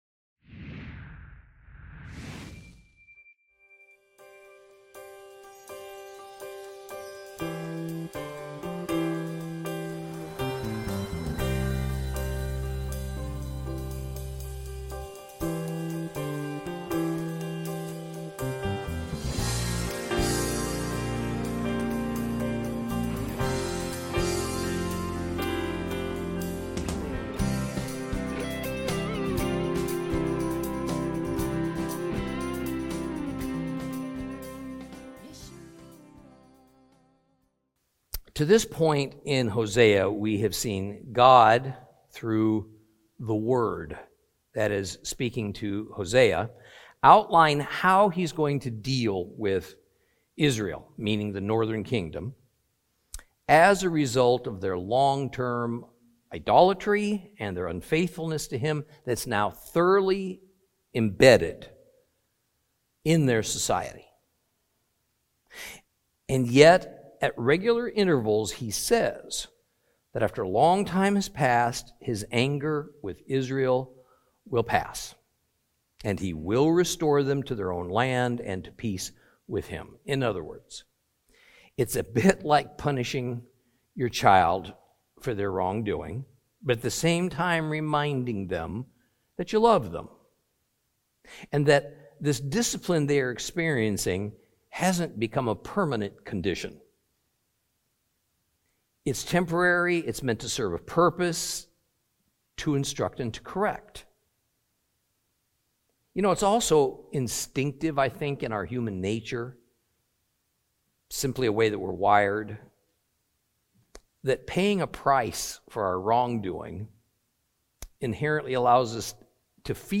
Teaching from the book of Hosea, Lesson 6 Chapters 2 and 3.